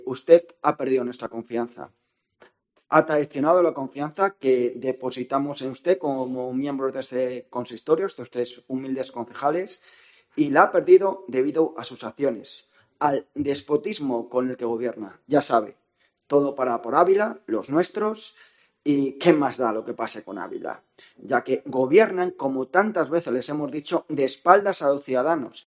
José Manuel Lorenzo Serapio, portavoz Vox. Pleno cuestión de confianza